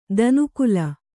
♪ danu kula